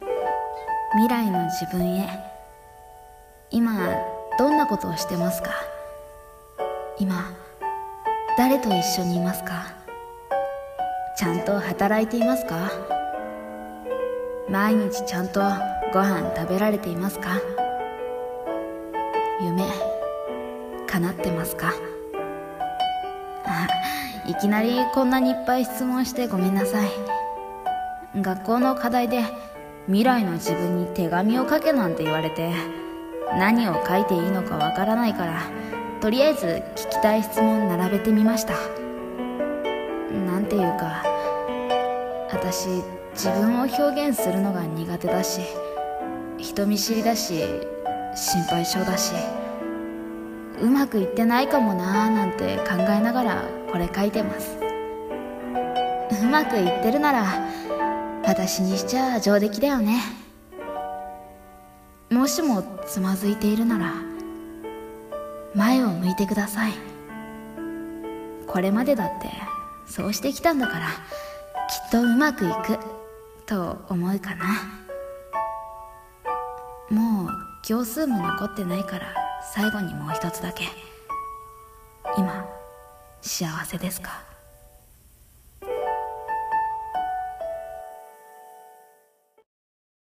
声劇 - 未来の自分へ